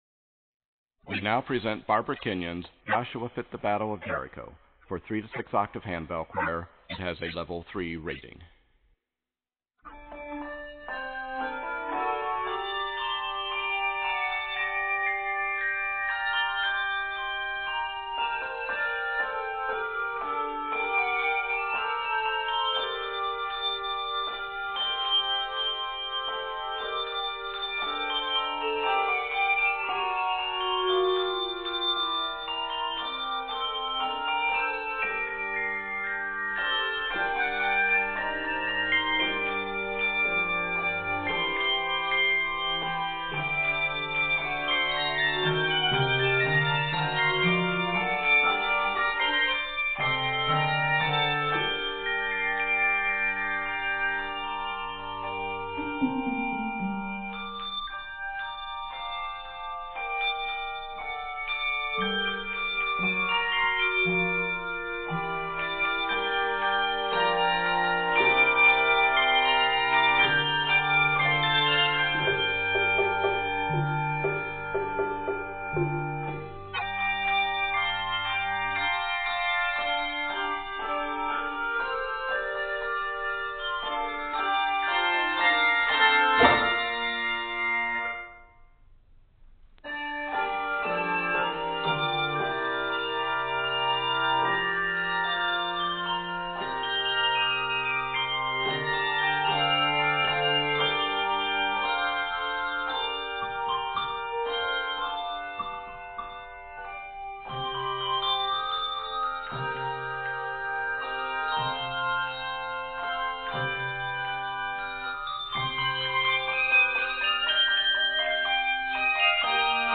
Octaves: 3-6